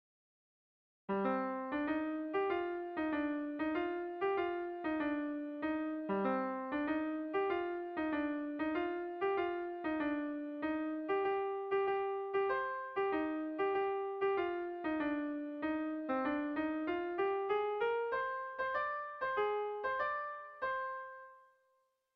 Lurra bihurtzen ari da - Melodías de bertsos - BDB.
Zortziko ertaina (hg) / Lau puntuko ertaina (ip)
AABD